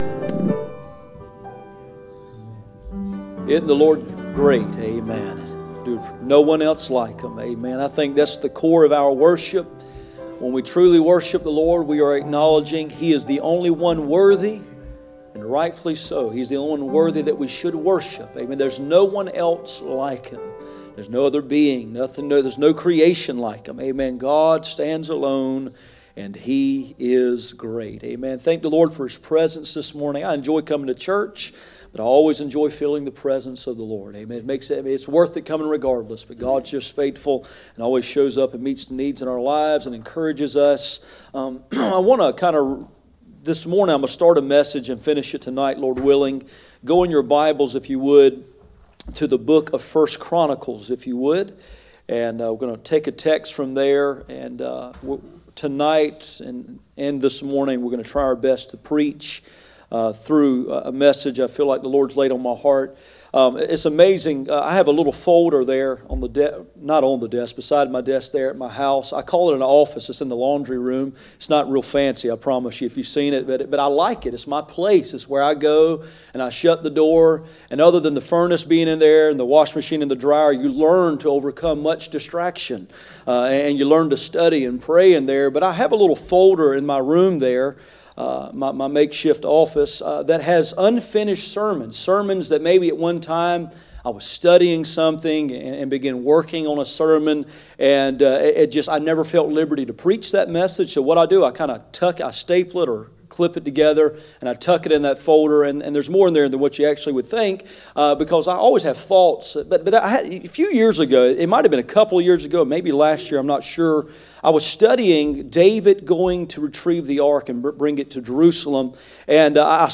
None Passage: 1 Chronicles 13:1-3, 1 Chronicles 15:1-2 Service Type: Sunday Morning